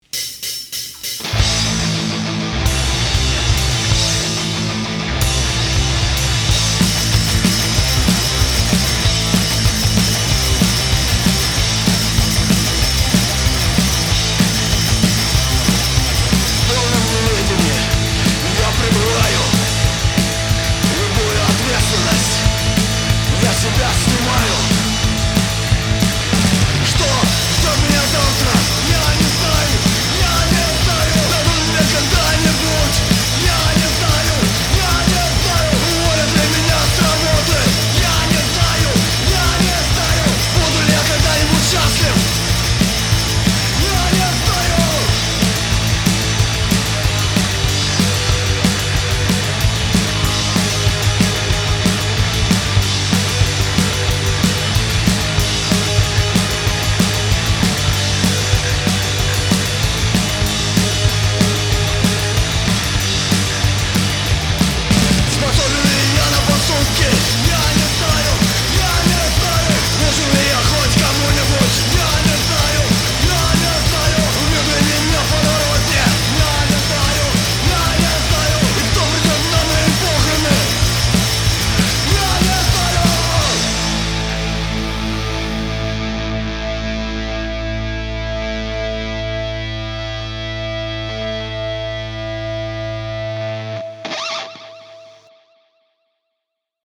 хардкор-группы